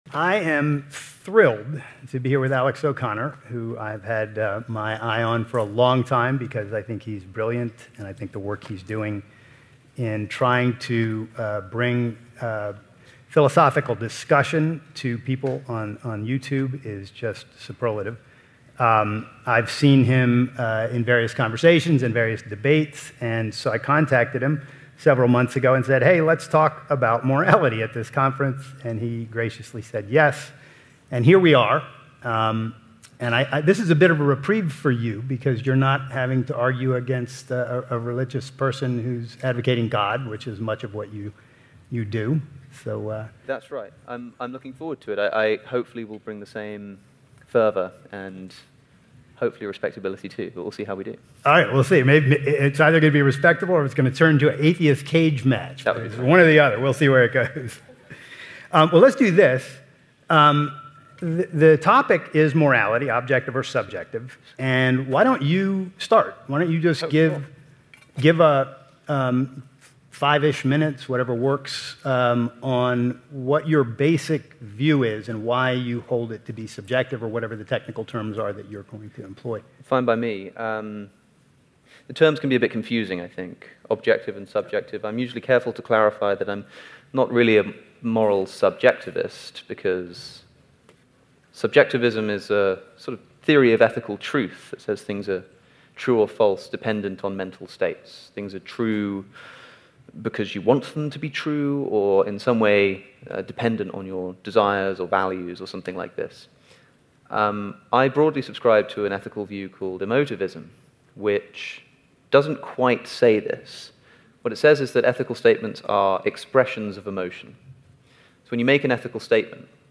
DEBATE Is Morality Objective